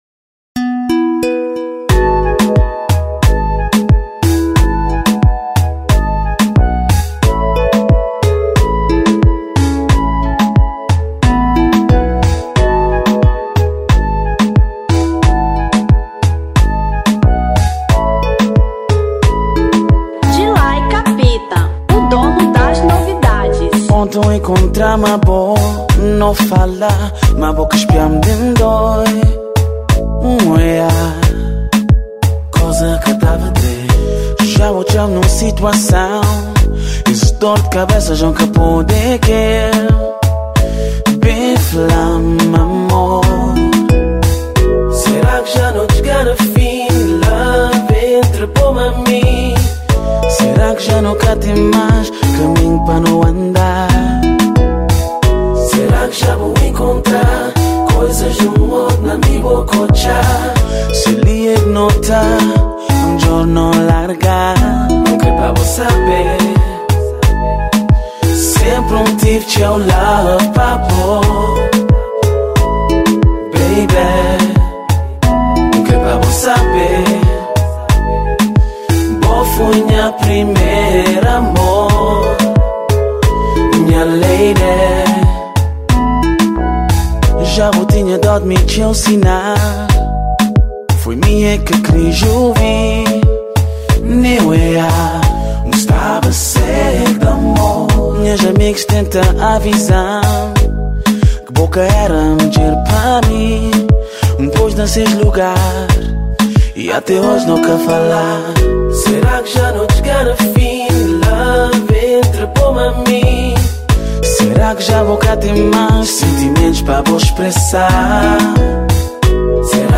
Kizomba 2010